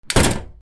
豪邸の玄関扉を閉める 強 02